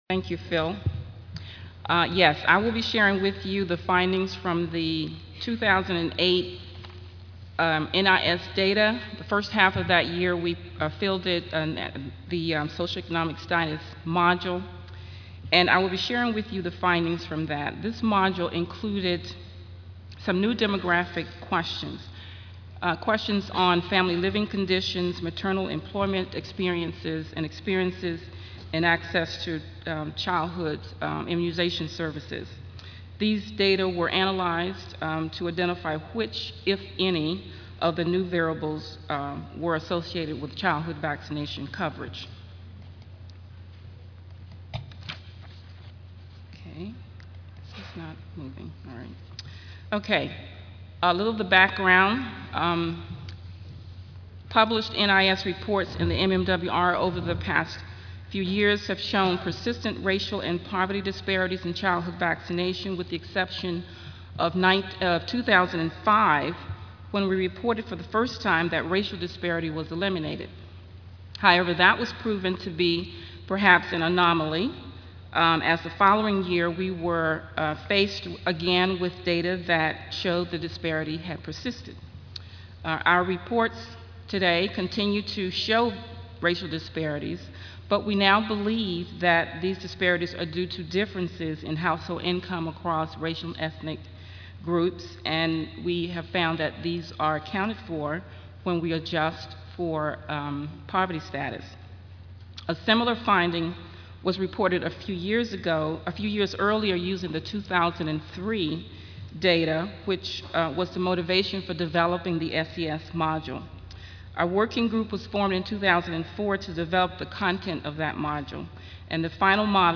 Centers for Disease Control and Prevention Audio File Slides Recorded presentation